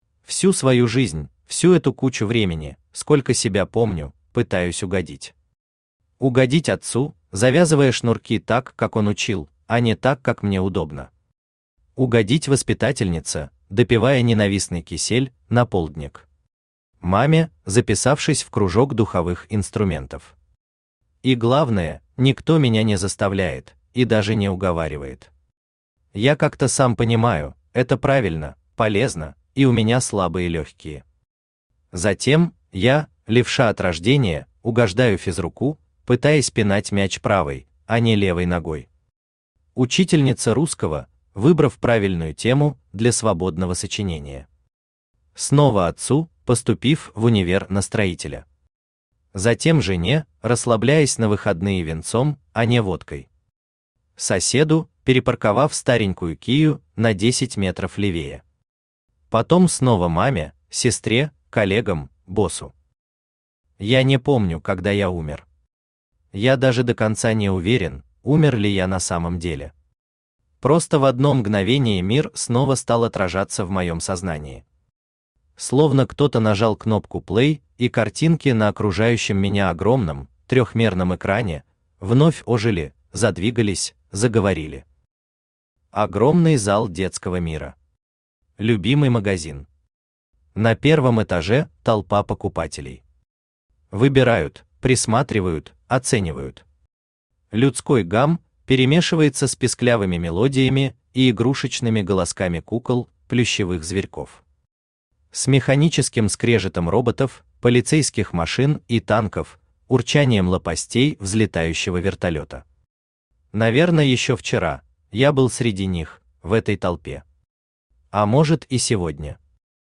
Аудиокнига Арчак | Библиотека аудиокниг
Aудиокнига Арчак Автор ШаМаШ БраМиН Читает аудиокнигу Авточтец ЛитРес.